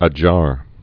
(ə-jär)